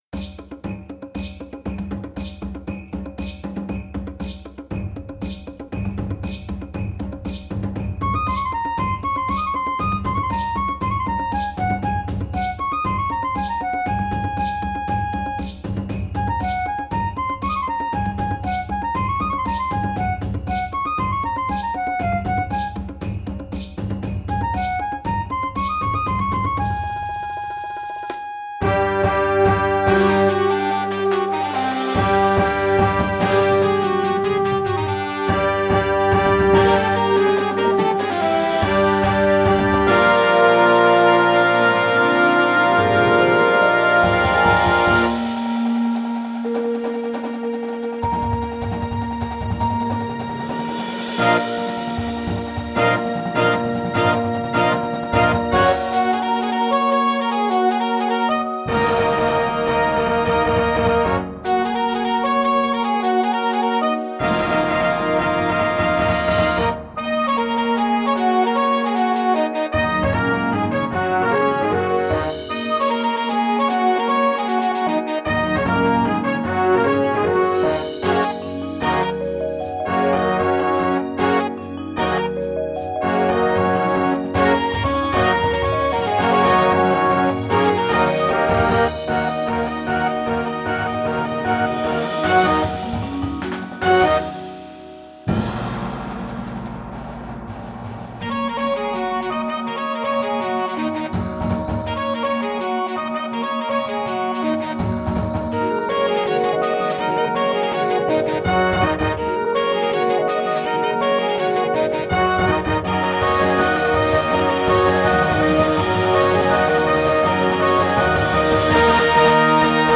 Instrumentation: Winds and Full Percussion